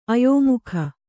♪ ayōmukha